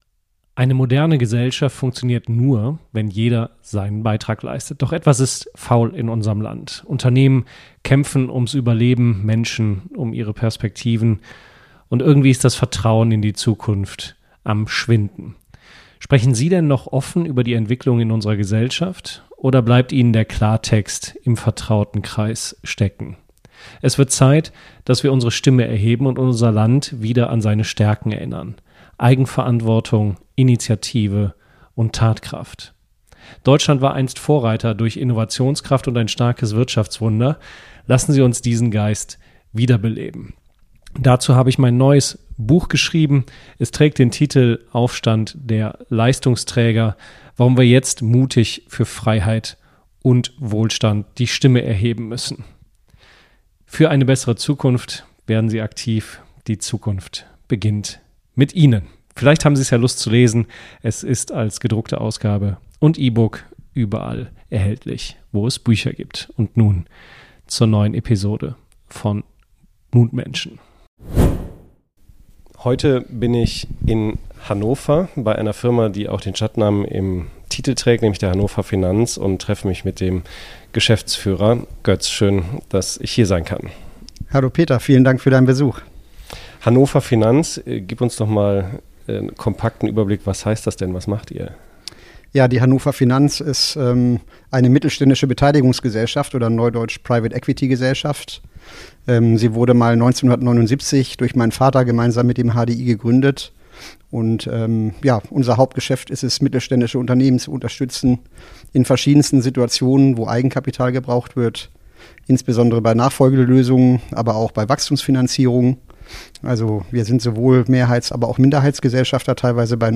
Ein Gespräch über Mut zur Veränderung, die Kunst, Menschen wirklich zu erreichen – und darüber, wie unternehmerischer Erfolg neu gedacht werden kann.